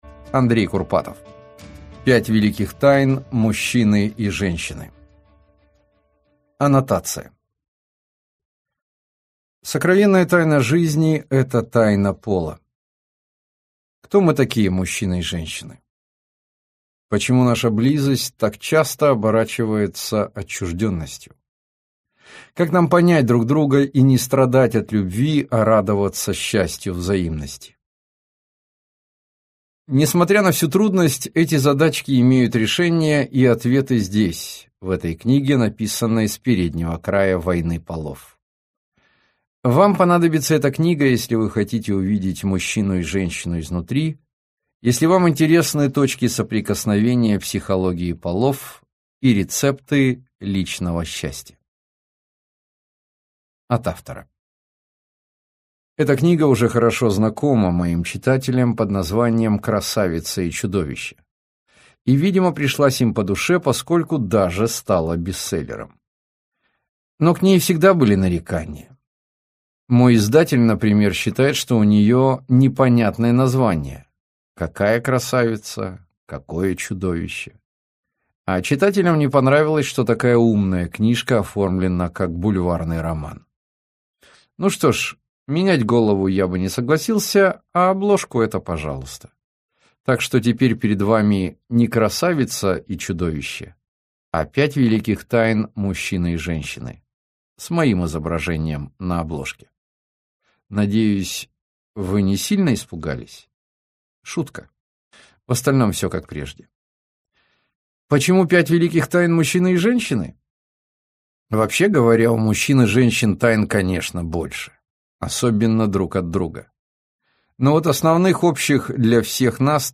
Аудиокнига Мужчина и женщина. Универсальные правила | Библиотека аудиокниг